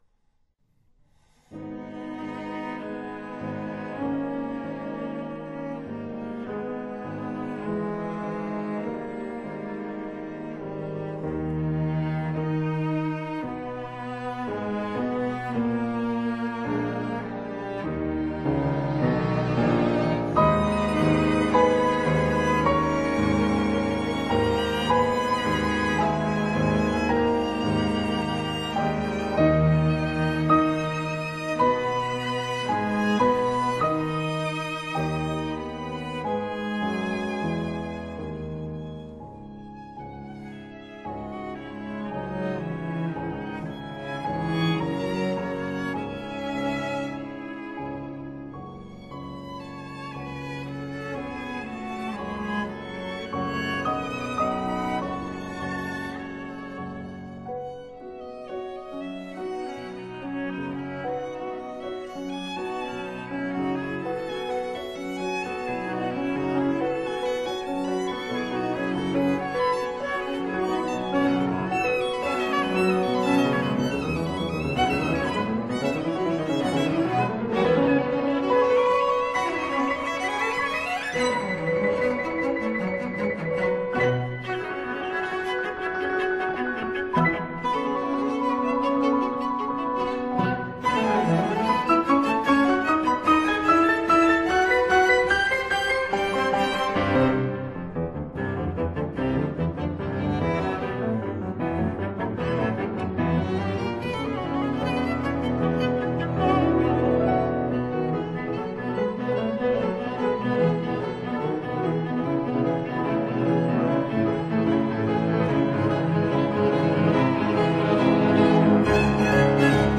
Piano Quartet in e minor
The finale starts with a slow Largo espressivo introduction followed by a somewhat Brahmsian Allegro vivace.